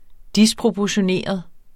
Udtale [ ˈdispʁobʌɕoˌneˀʌð ]